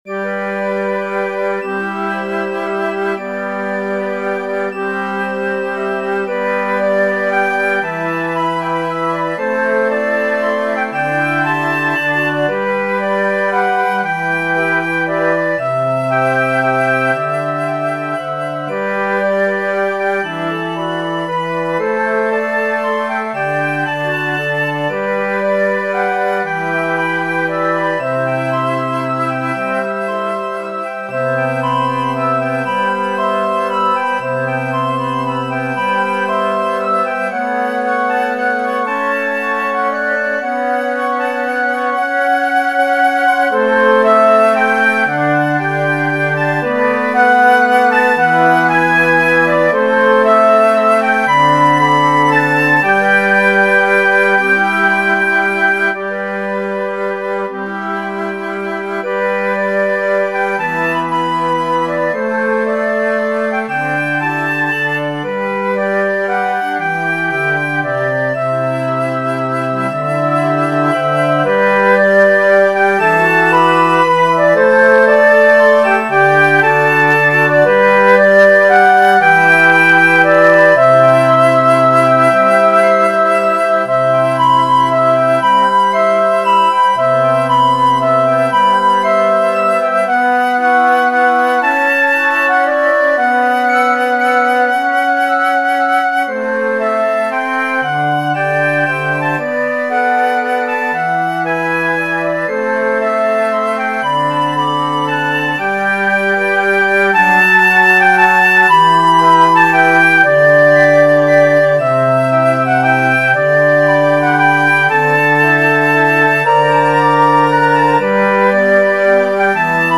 This arrangement is for flute choir.